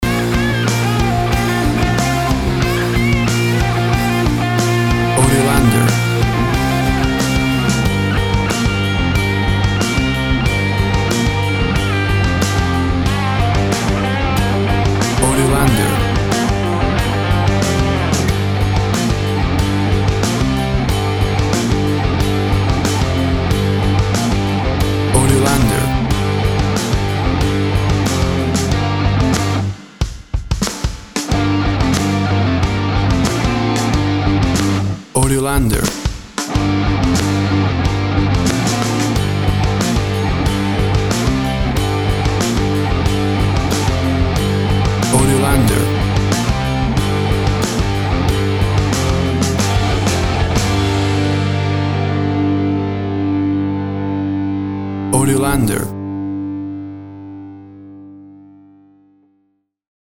1970´s Classic heavy metal Rock.
Tempo (BPM) 93